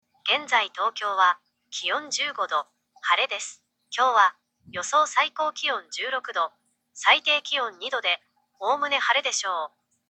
アップグレードされたスピーカーにより、Geminiの音声応答がより聞き取りやすくなった。
▽音声応答の録音音声▽